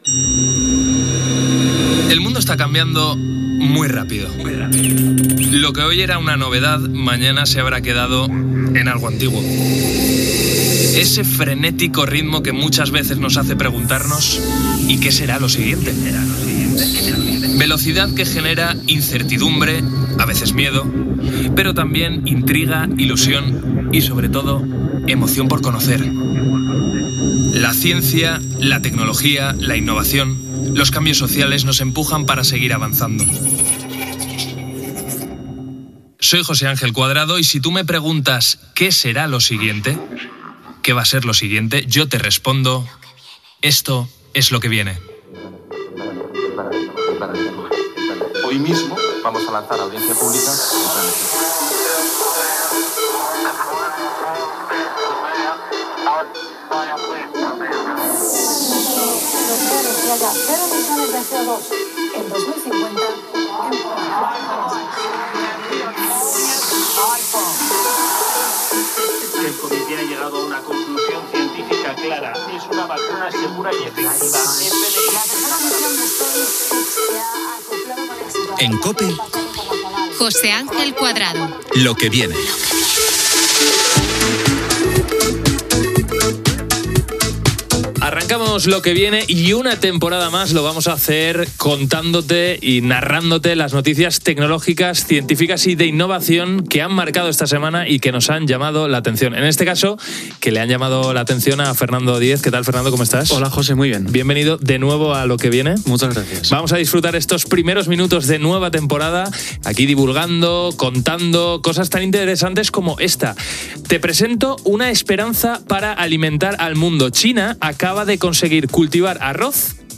Fragment del primer programa de la temporada 2025-2026.
Divulgació
FM